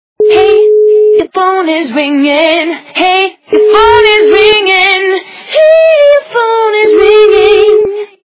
реалтон Женский голос поет на английском